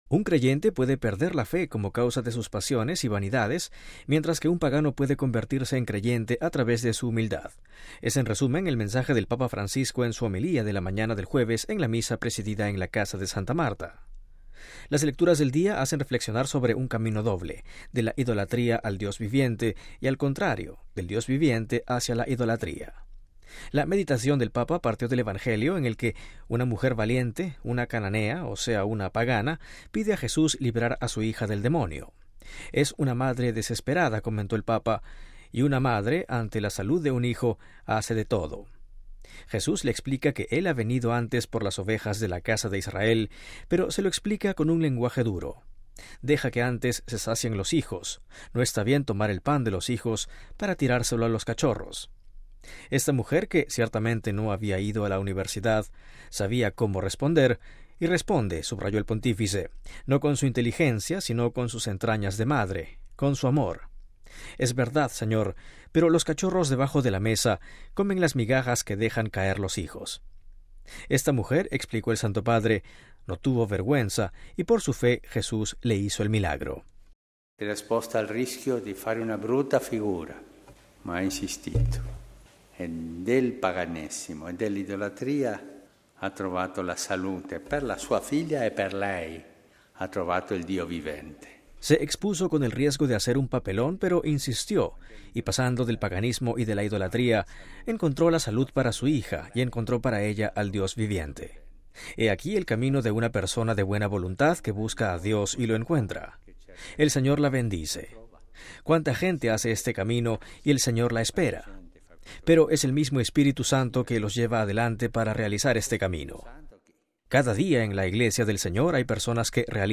MP3 Un creyente puede perder la fe como causa de sus pasiones y vanidades, mientras que un pagano puede convertirse en creyente a través de su humildad: es en resumen, el mensaje del Papa Francisco en su homilía de la mañana del jueves en la Misa presidida en la Casa de Santa Marta.